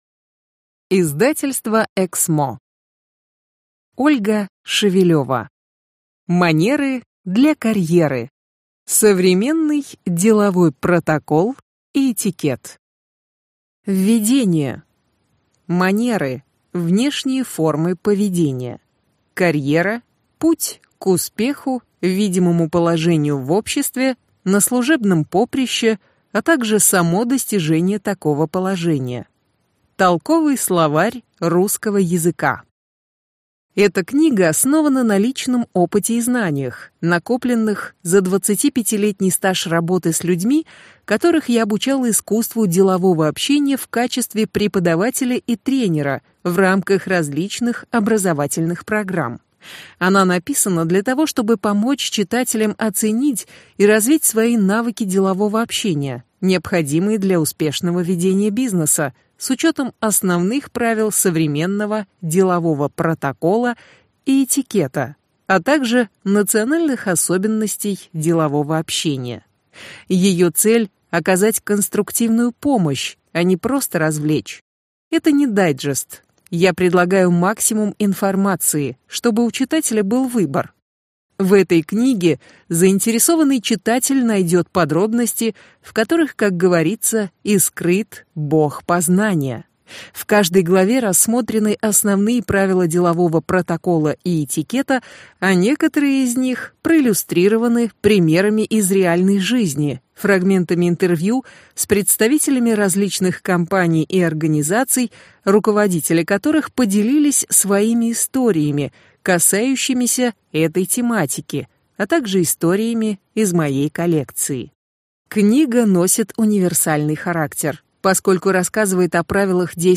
Аудиокнига Манеры для карьеры. Современный деловой протокол и этикет | Библиотека аудиокниг